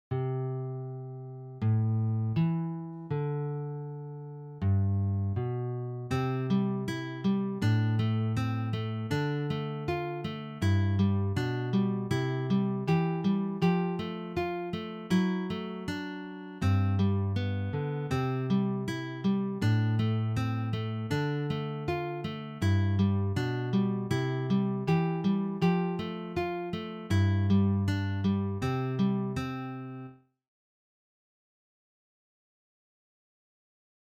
Neue Musik
Sololiteratur
Gitarre (1)